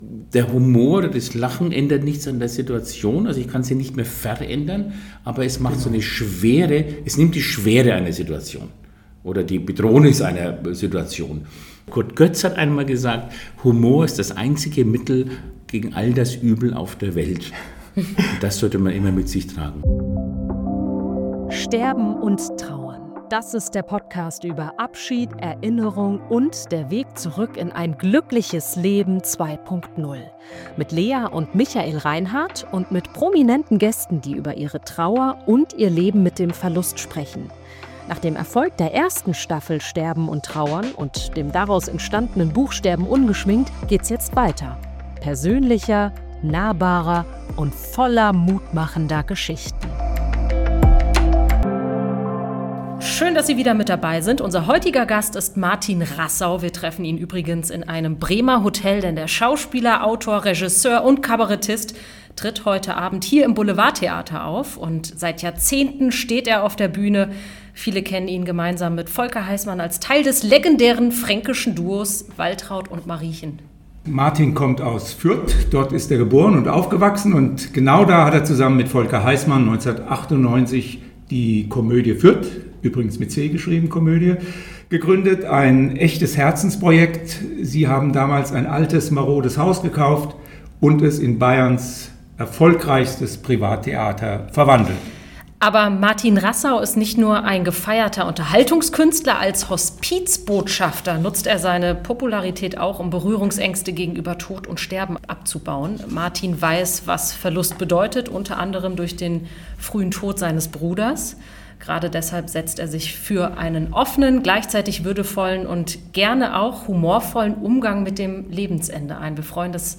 Ein intensives, berührendes und gleichzeitig leichtes Gespräch über Verluste, das Leben nach dem Abschied – und die Kraft, die im Lachen liegt.